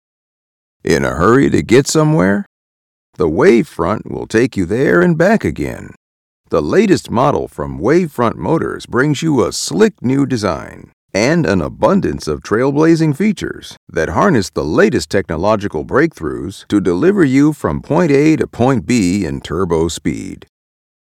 Warm, wise and comforting.
Cowboy Style
General American, African American, Western, South and New York accents.
Middle Aged